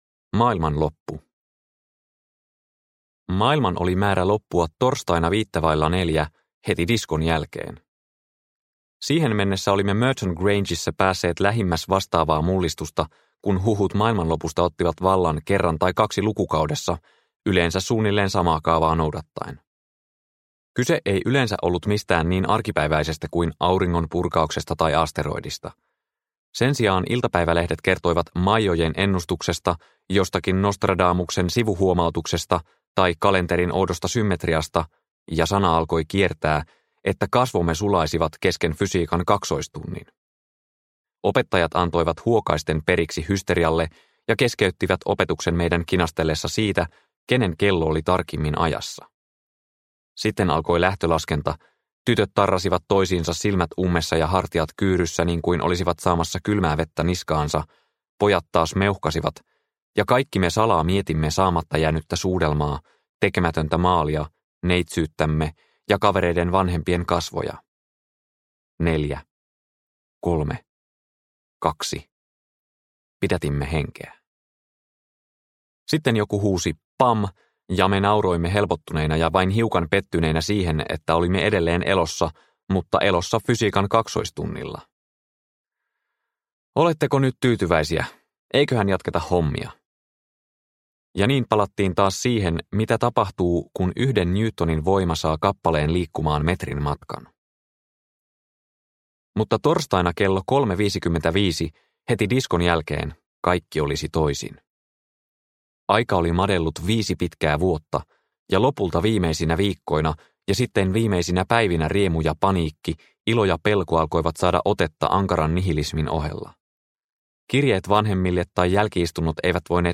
Suloinen suru – Ljudbok – Laddas ner